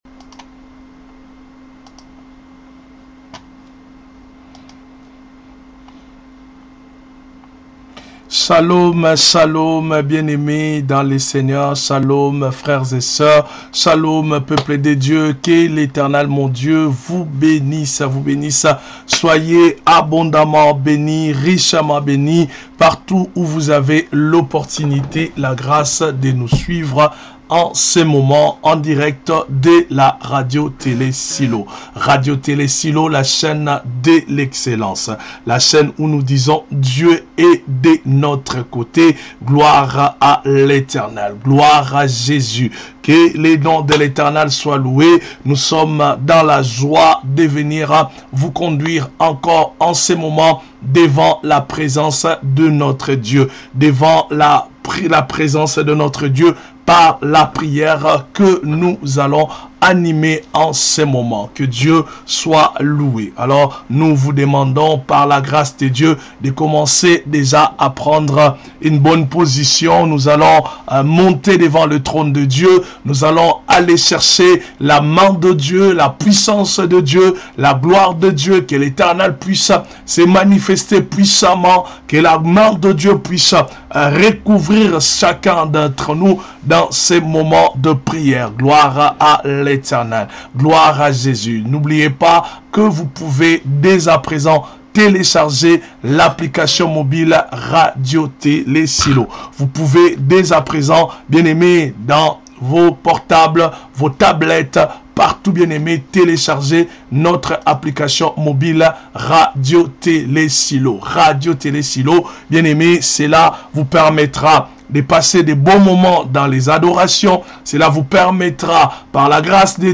Prière contre les surveillances et contrôle maléfique 2ème Partie